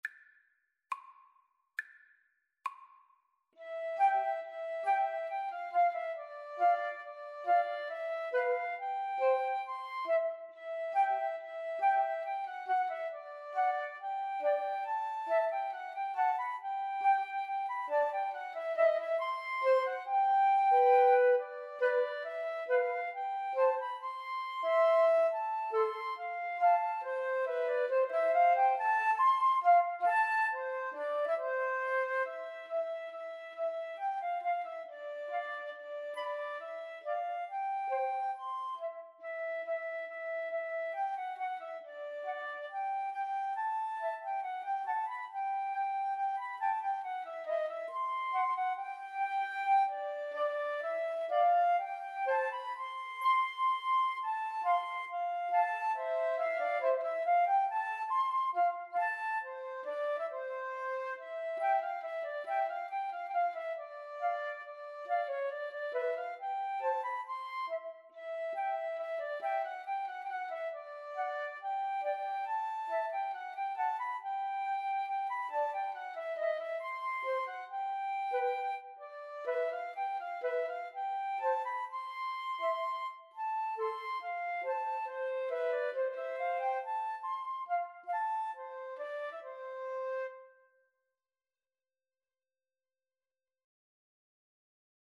Flute Trio version
= 69 Lieblich (tenderly)
2/4 (View more 2/4 Music)
Flute Trio  (View more Intermediate Flute Trio Music)
Classical (View more Classical Flute Trio Music)